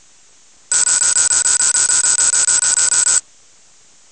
CartKeyUnlock78.wav